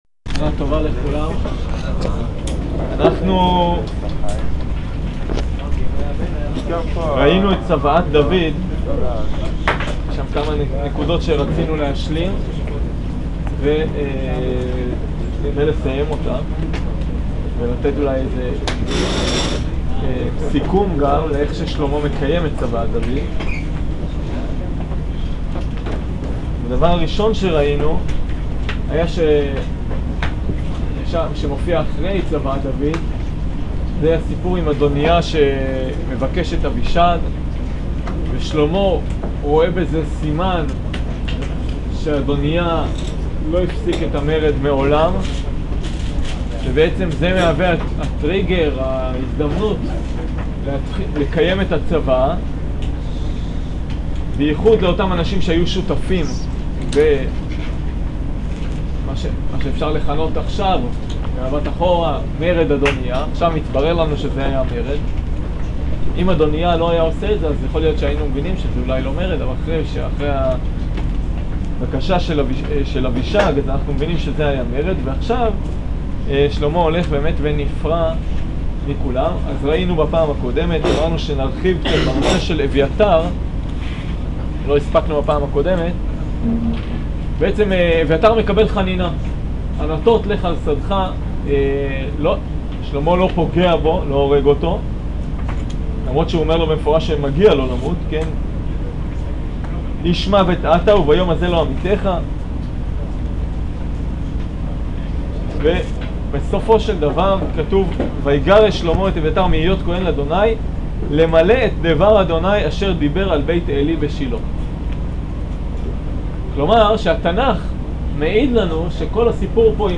שיעור פרק ב